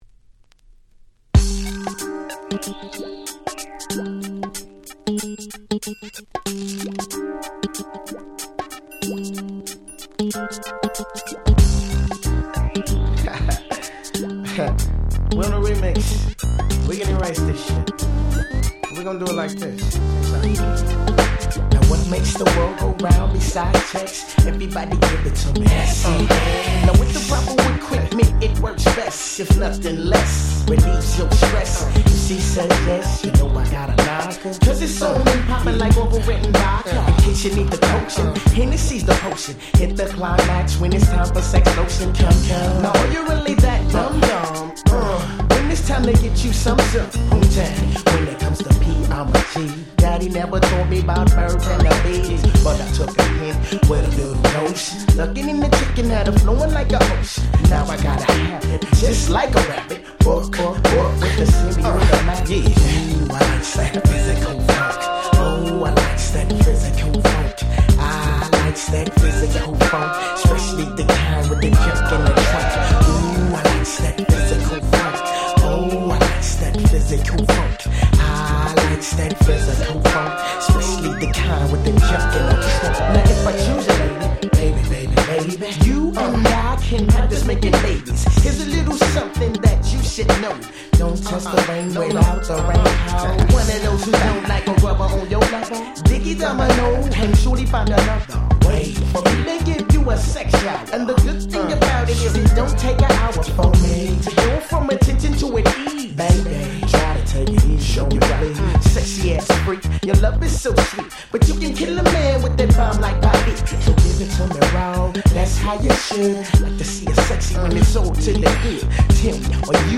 95’ Very Nice West Coast Hip Hop / G-Funk !!
もう最高にレイドバックしたSmoothなG-Funkに仕上がっております！！
G-Rap Gangsta Rap ウエストコースト ウエッサイ